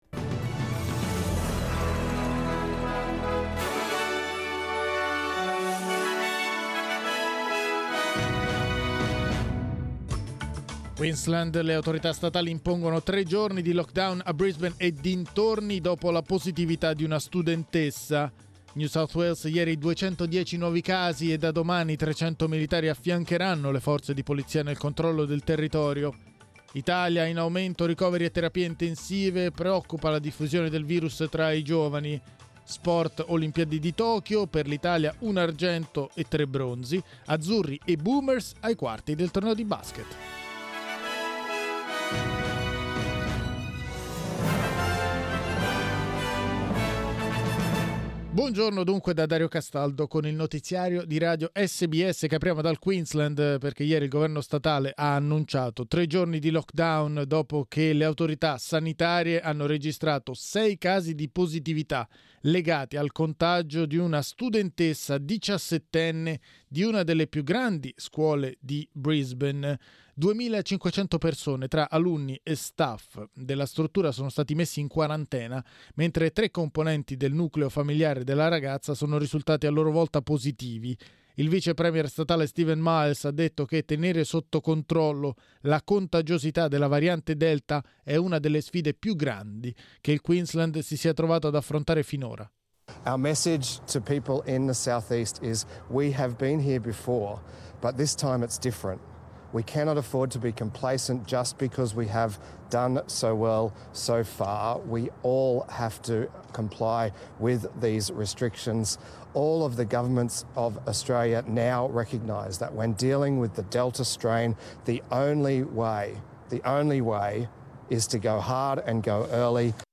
Il notiziario di SBS in italiano.